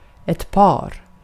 Ääntäminen
US : IPA : [pɛɹ]